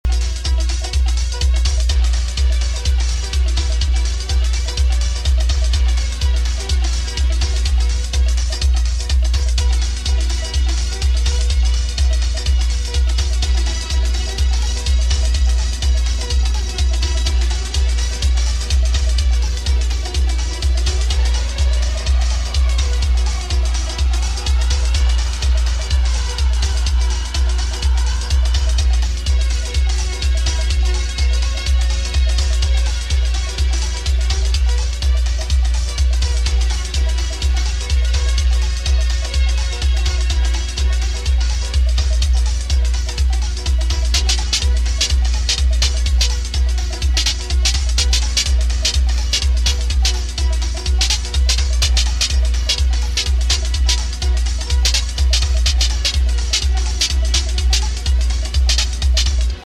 ホーム > HOUSE/BROKEN BEAT > V.A